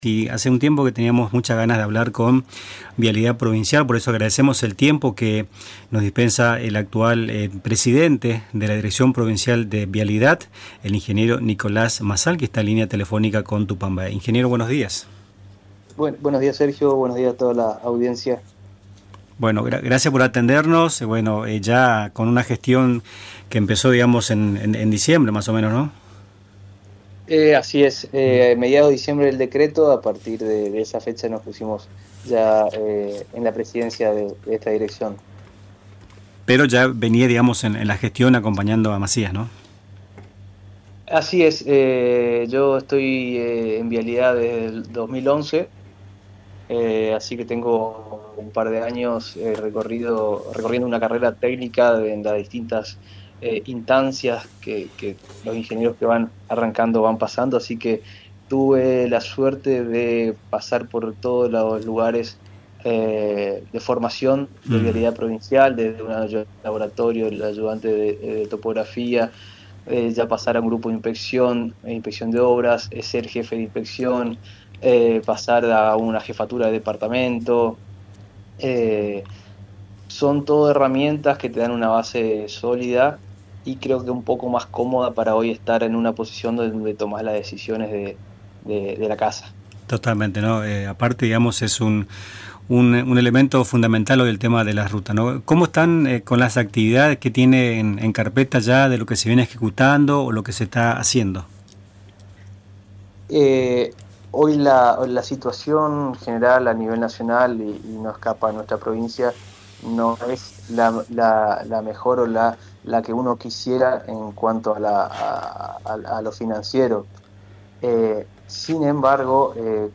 Mazal Bazán, designado en diciembre de 2025 por el gobernador Hugo Passalacqua, dialogó en Nuestras Mañanas y repasó el panorama actual del sistema vial misionero, marcado por limitaciones financieras pero con una planificación que busca sostener obras clave.
Escuchá la entrevista completa realizada en Radio Tupambaé: